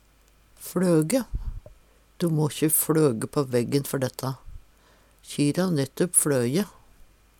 Tilleggsopplysningar Det vert sagt å flyge "fLyge- fLyg-fLaug-fLøje"